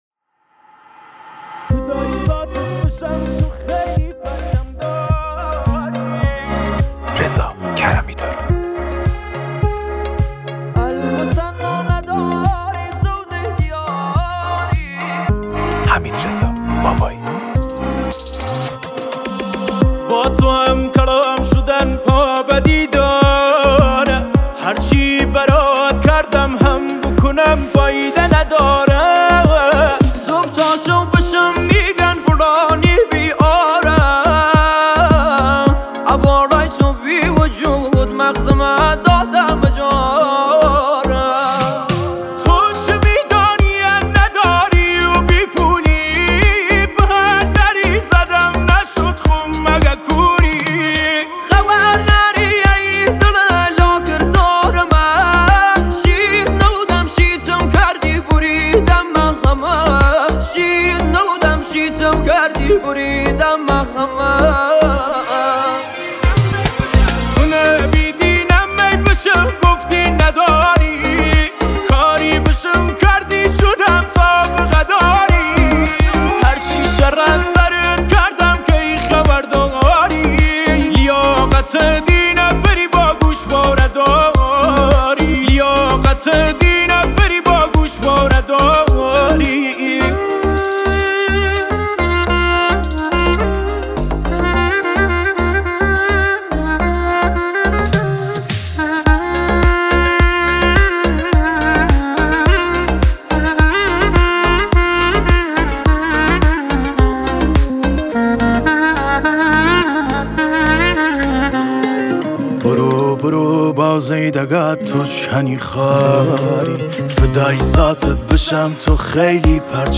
شنیدنی و احساسی